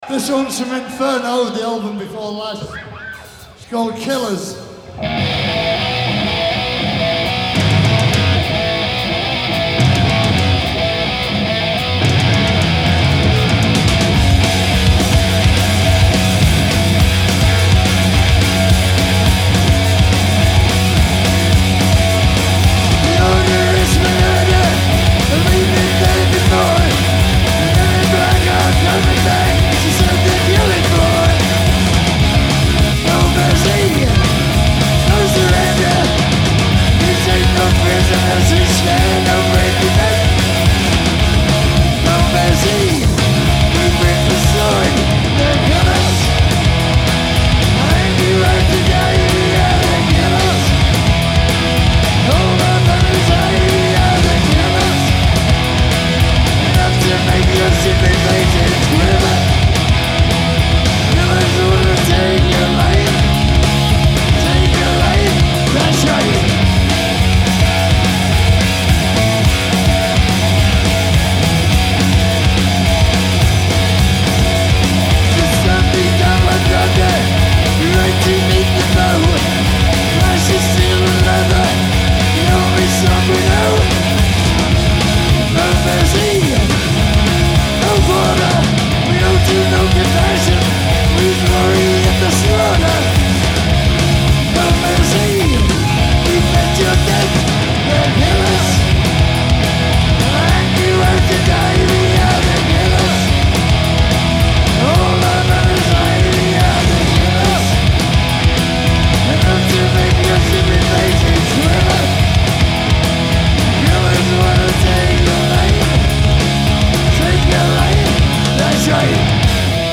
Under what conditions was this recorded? Hi-Res Stereo